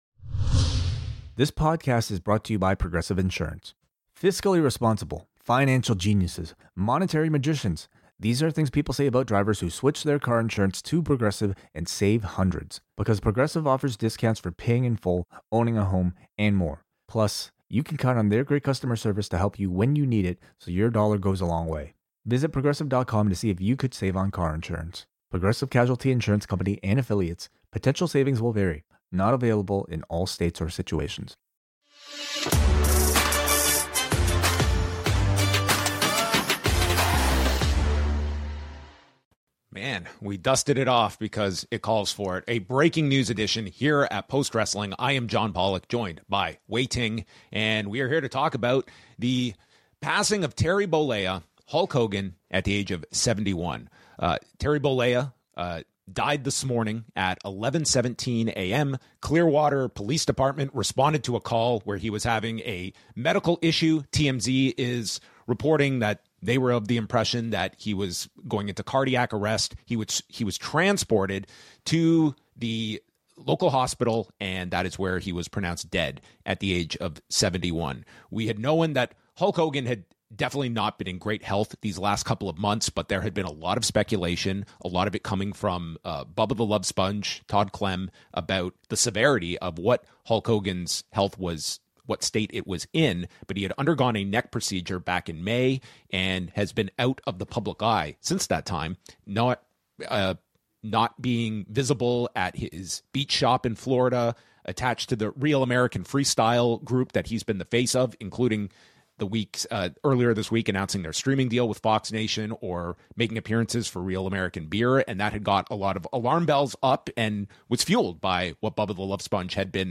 Queen of the Ring: Interview